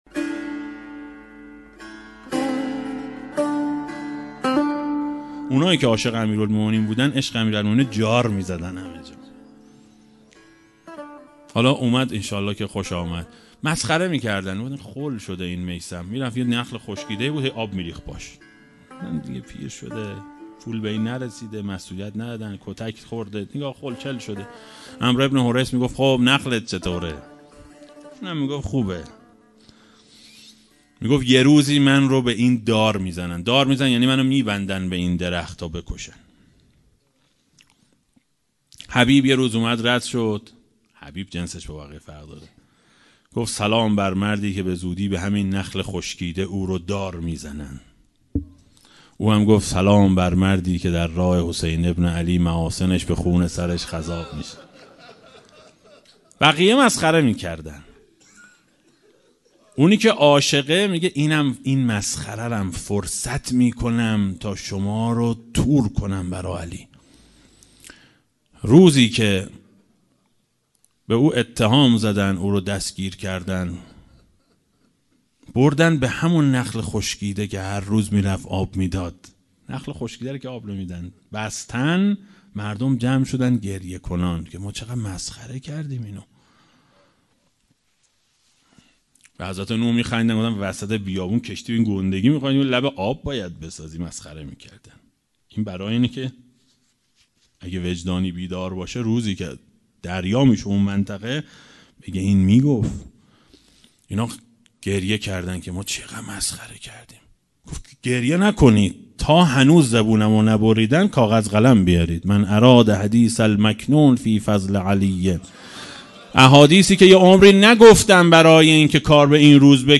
برگرفته از جلسه سیزدهم «سیر تکوّن عقاید شیعه»؛ ماه مبارک رمضان 1401 هجری شمسی